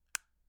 SmartPhoneHoldButton.wav